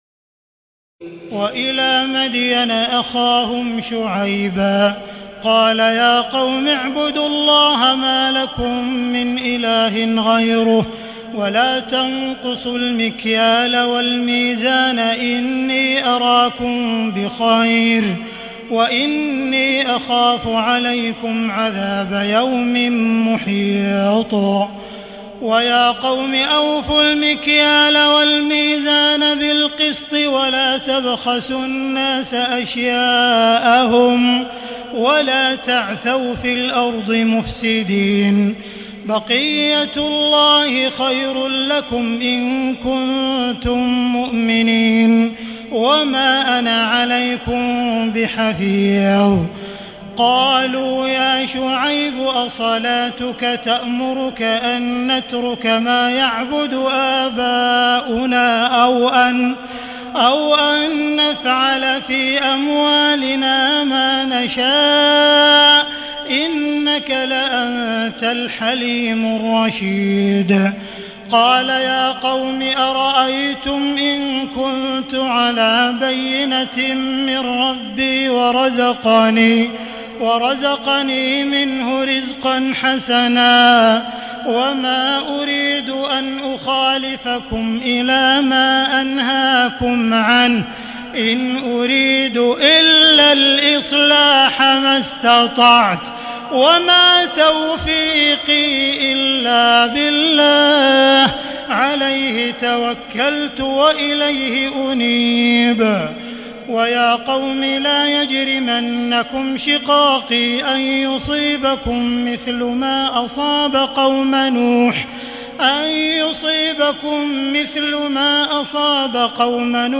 تراويح الليلة الحادية عشر رمضان 1437هـ من سورتي هود (84-123) و يوسف (1-53) Taraweeh 11 st night Ramadan 1437H from Surah Hud and Yusuf > تراويح الحرم المكي عام 1437 🕋 > التراويح - تلاوات الحرمين